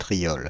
Ääntäminen
Ääntäminen France (Île-de-France): IPA: /tʁjɔl/ Haettu sana löytyi näillä lähdekielillä: ranska Käännöksiä ei löytynyt valitulle kohdekielelle.